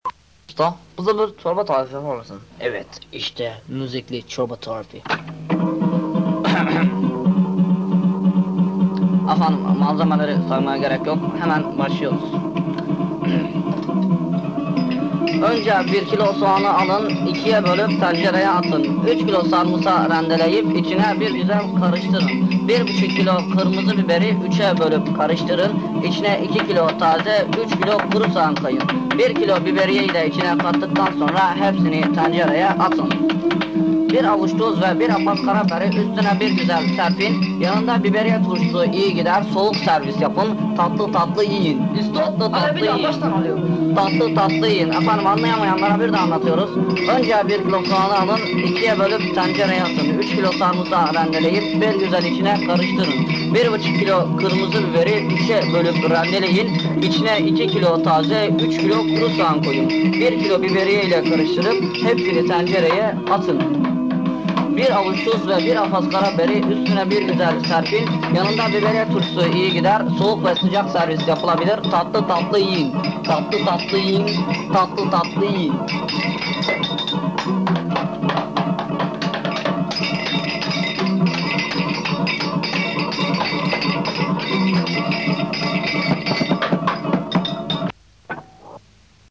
çılgın şarkı